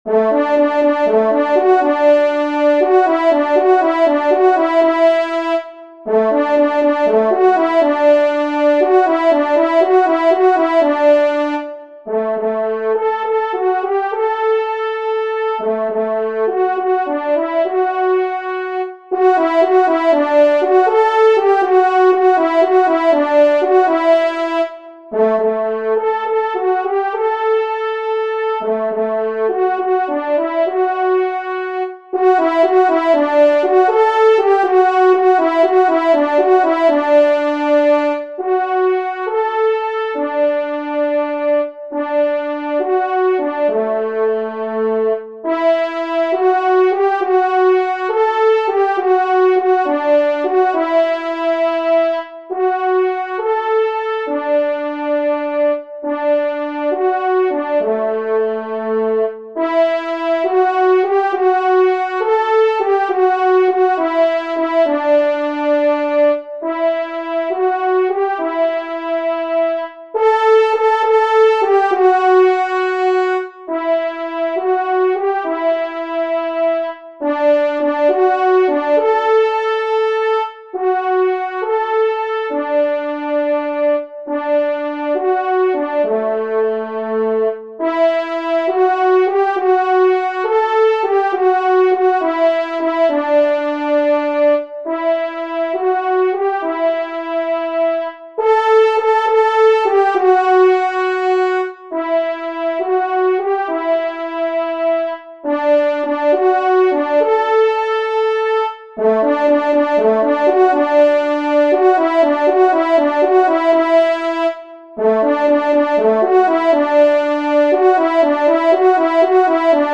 Genre :  Divertissement pour Trompes ou Cors
Pupitre 1° Cor